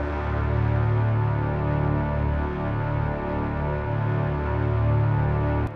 Orchestra
d3.wav